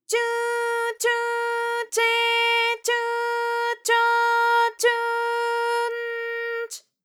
ALYS-DB-001-JPN - First Japanese UTAU vocal library of ALYS.
chu_chu_che_chu_cho_chu_n_ch.wav